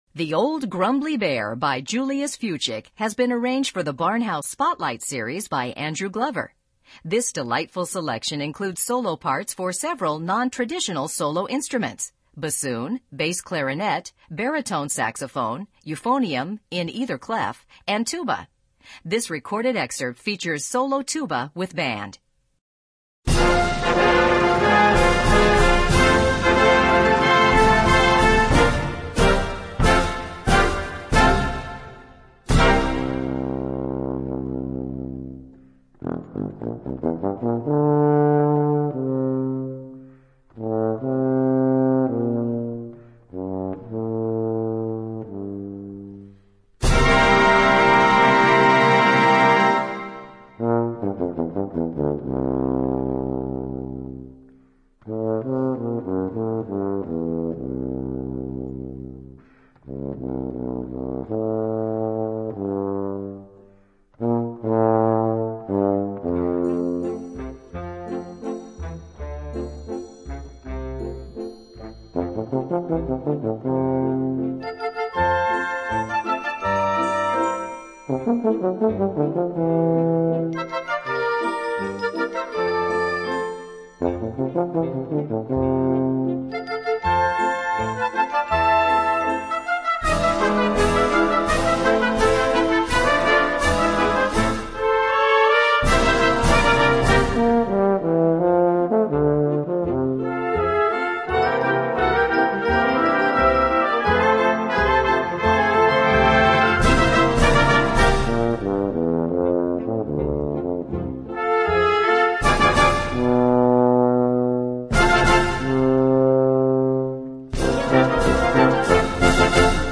Voicing: Instrument Solo w/ Band